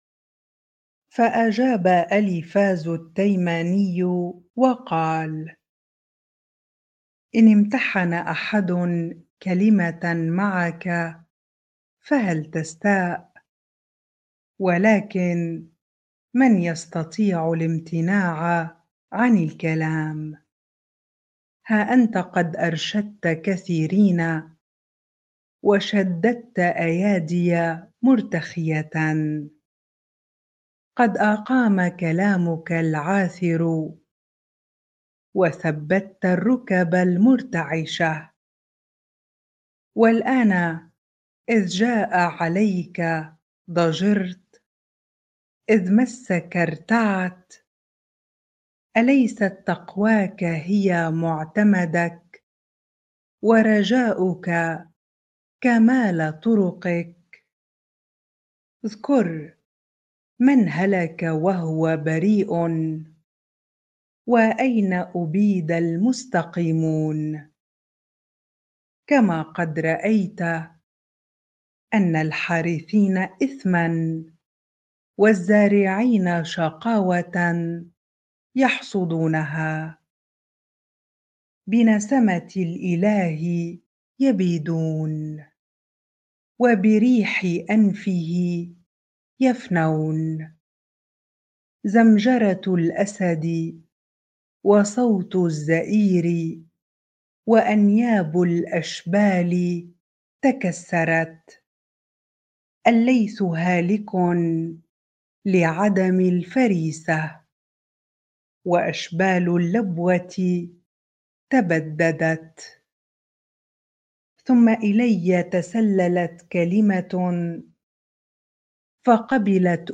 bible-reading-Job 4 ar